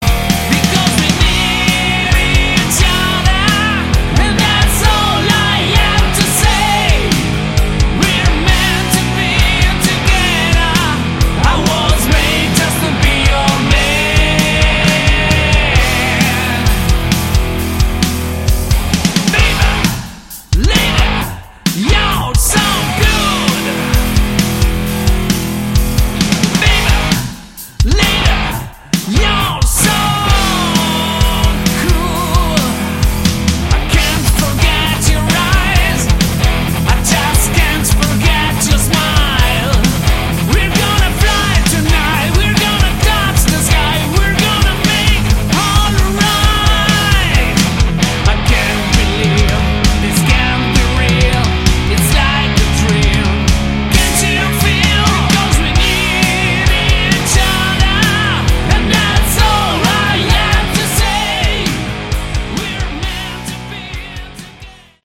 Category: Sleaze Glam
Vocals
Bass, Backing Vocals
Guitar
Drums, Backing Vocals